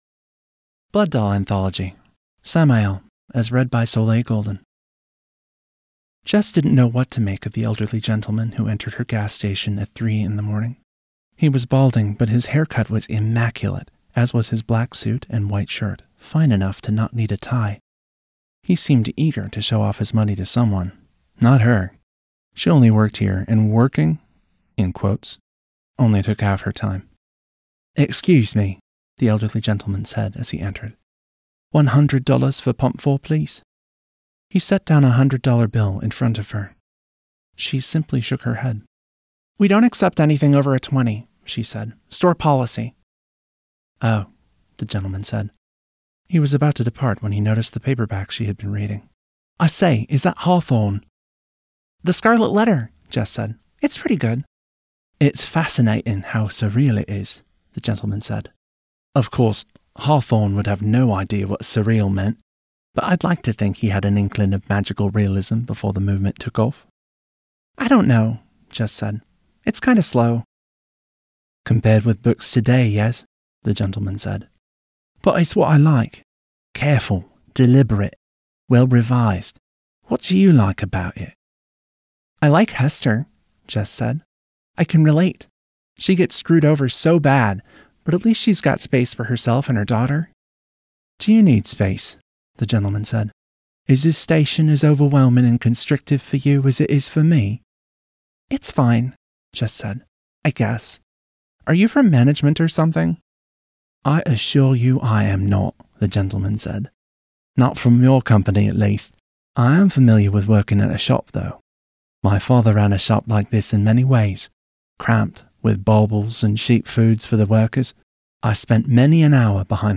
A series of vignettes about the characters in Vampire:The Masquerade: Blood Doll, read by their actors.